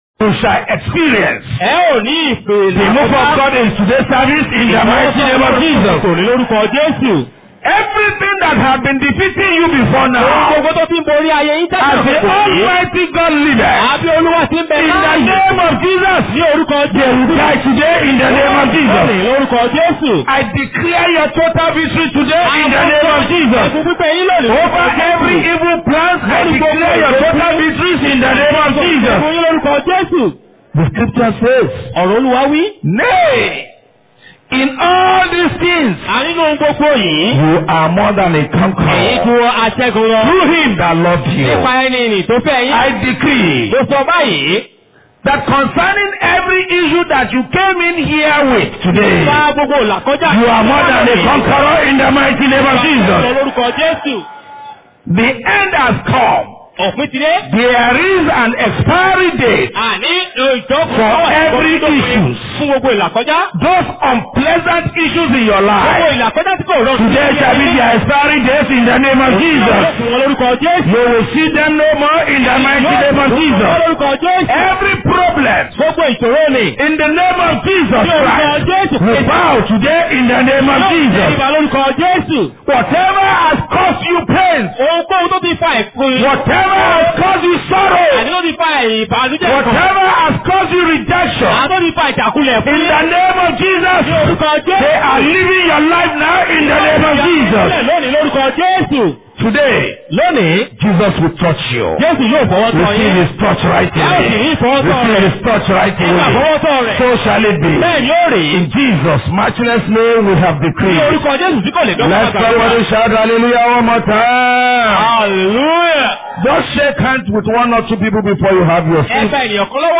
Posted in Sunday Service